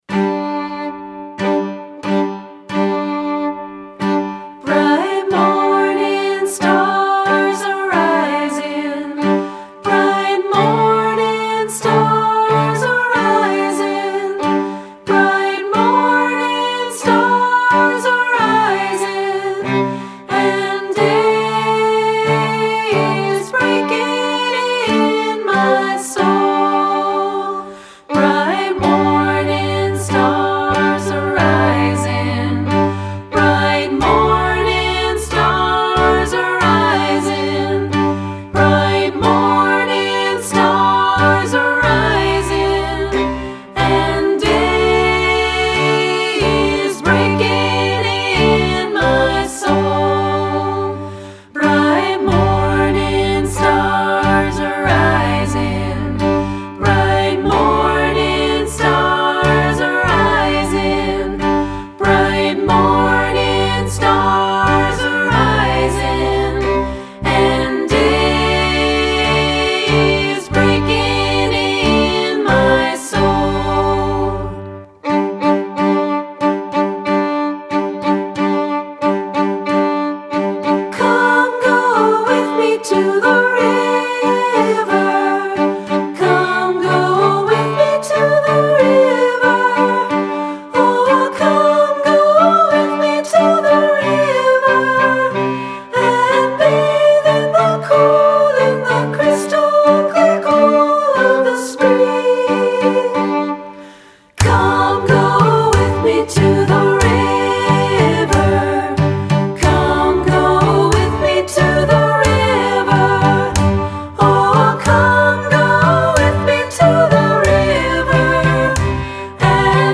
Voicing: Any Combination